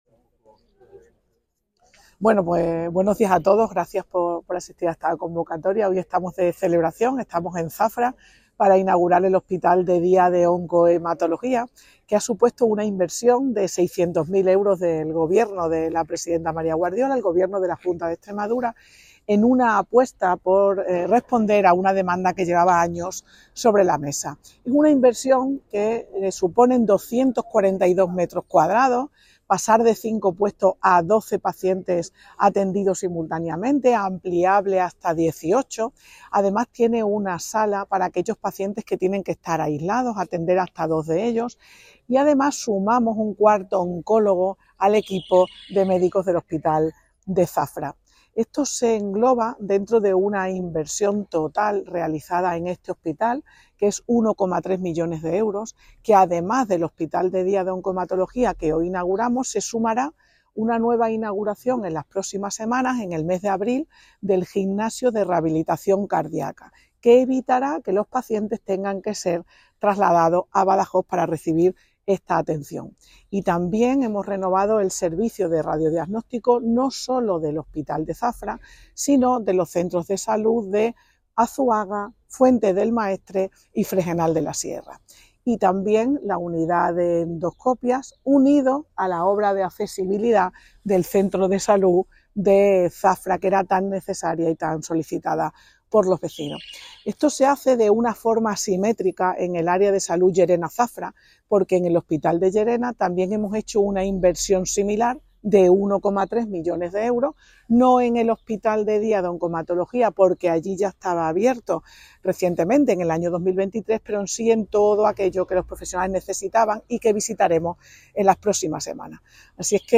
Noticias La consejera de Salud inaugura el nuevo Hospital de D�a Oncohematol�gico de Zafra, cuya inversi�n es de 600.000 euros 28/03/2025 SALUD EXTREMADURA LLERENA-ZAFRA Documentos relacionados Declaraciones consejera de Salud .